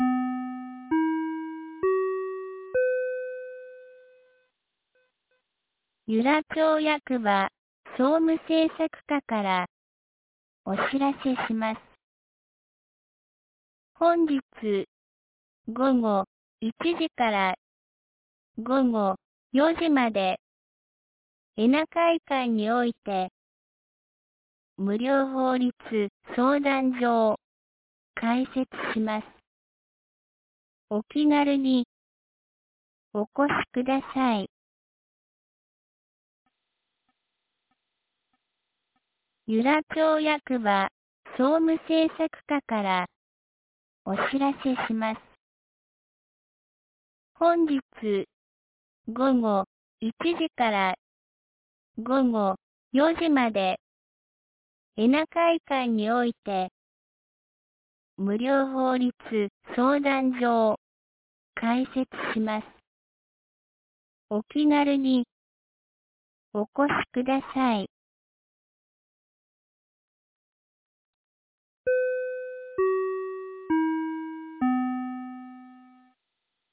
2025年08月07日 07時51分に、由良町から全地区へ放送がありました。